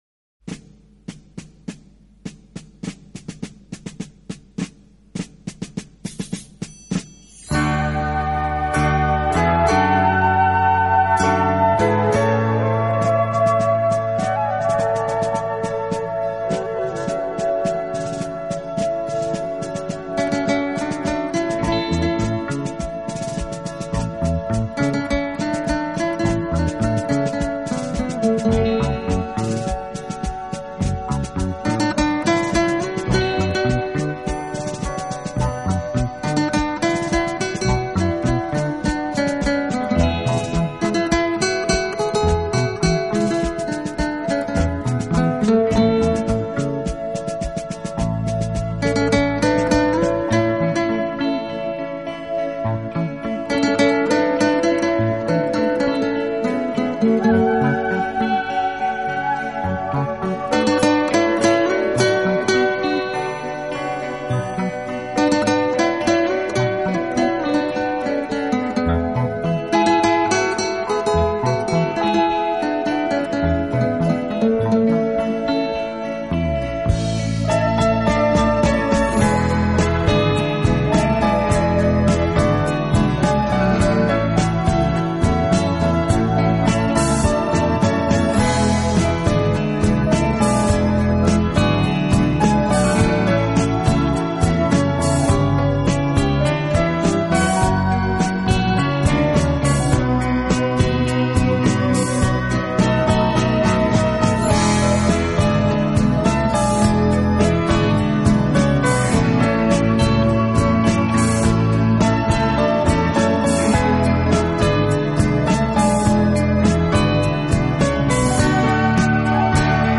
大多轻拉轻弹轻敲，给人安宁、清静、舒适的感觉。
温柔、宁静、娓娓动听。
是乐队演奏的主要乐器，配以轻盈的打击乐，使浪漫气息更加浓厚。